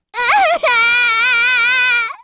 Hanus-Vox_Sad_kr.wav